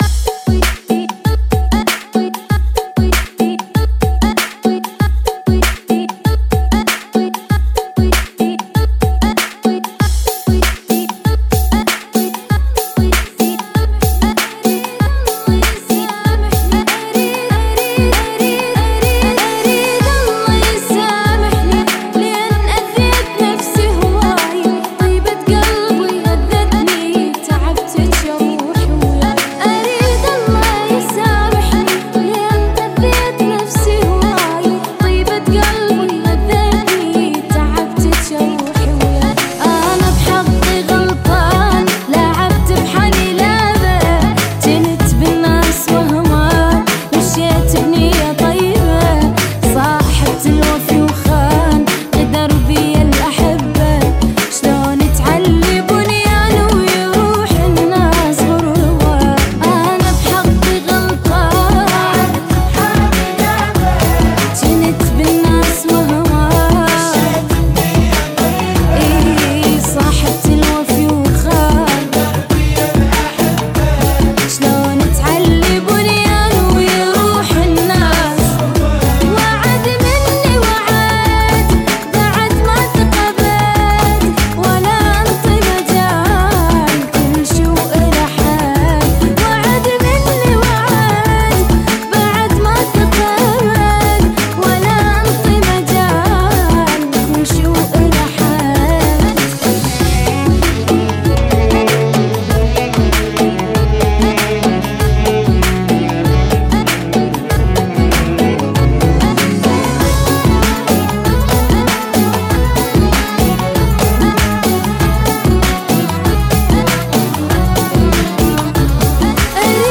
96 Bpm